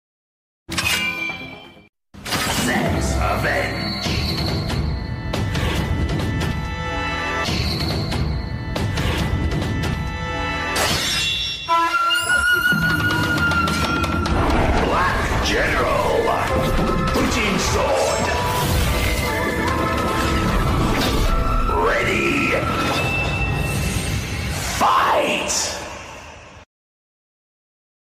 Kamen Rider Tycoon Bujin sword henshin sound